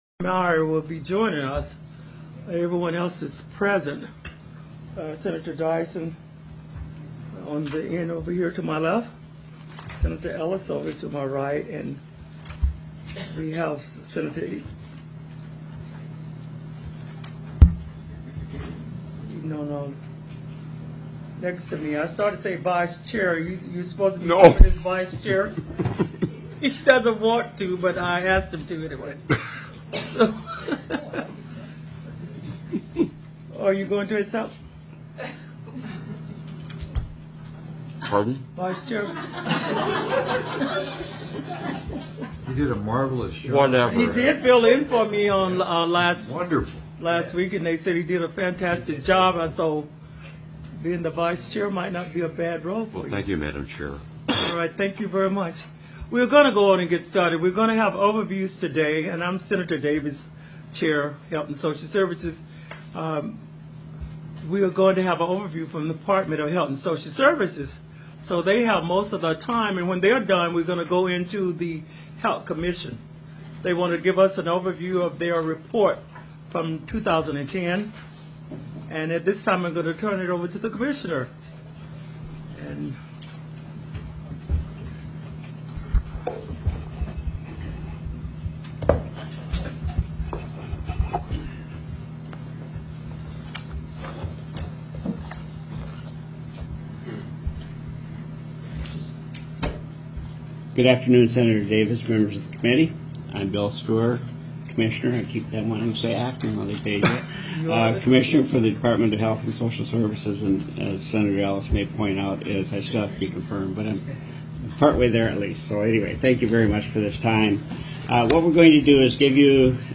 02/07/2011 01:30 PM Senate HEALTH & SOCIAL SERVICES
TELECONFERENCED